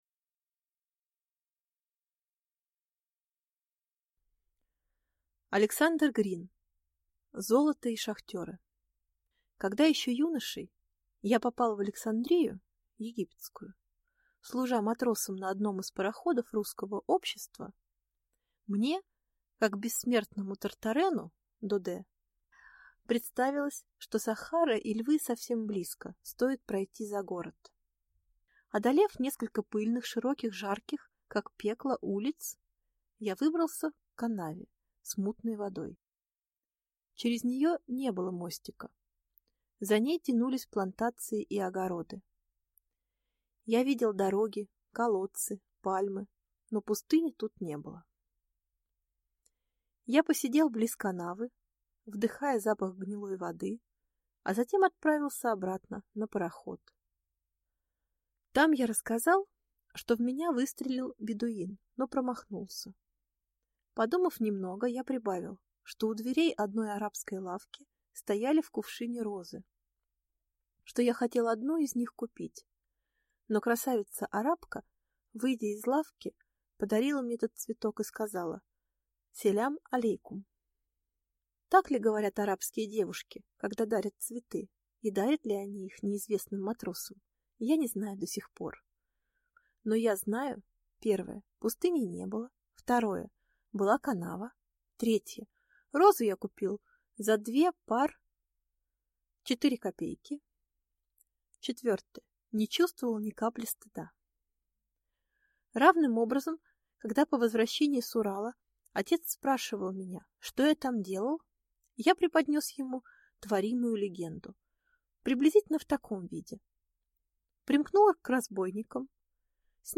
Аудиокнига Золото и шахтеры | Библиотека аудиокниг